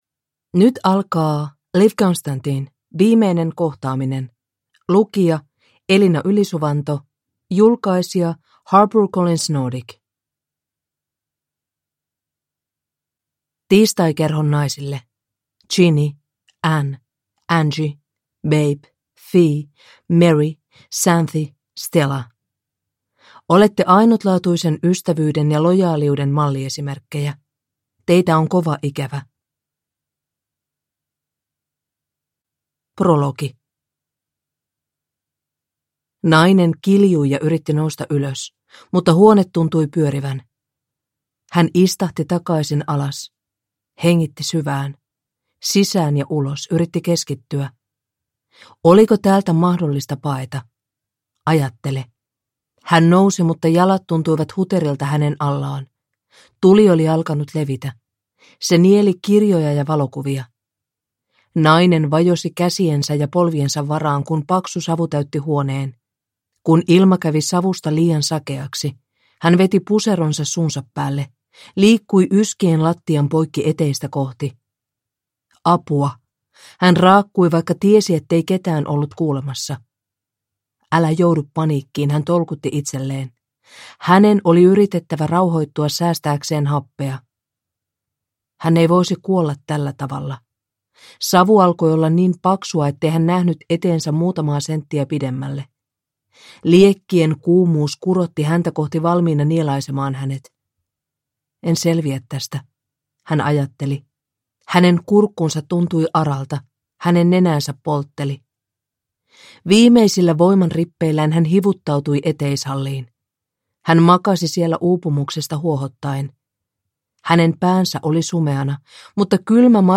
Viimeinen kohtaaminen – Ljudbok – Laddas ner